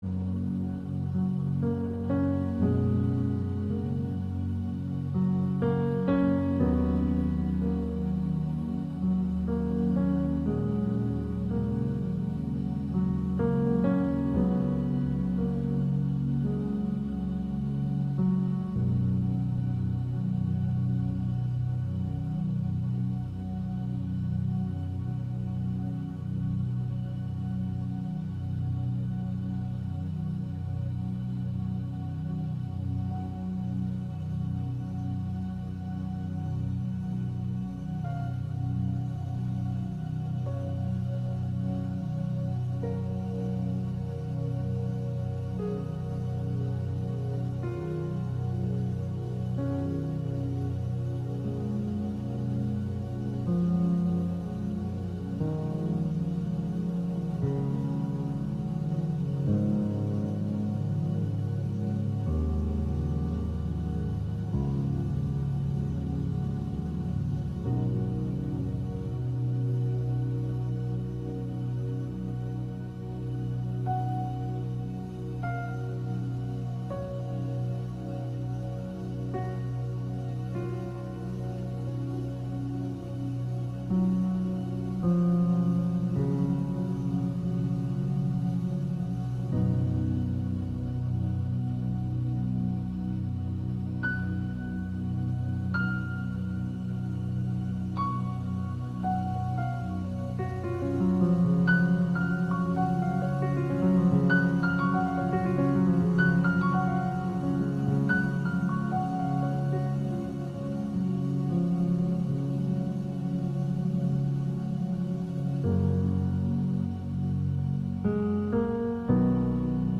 Morning Worship
August 2 Worship Audio